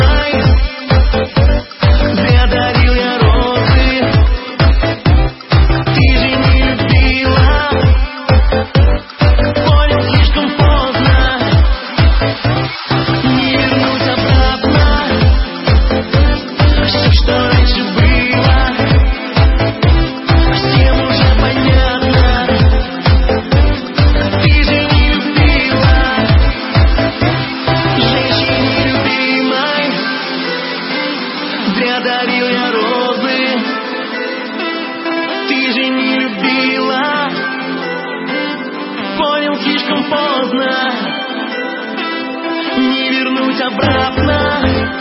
Зона обмена: Музыка | Танцевальная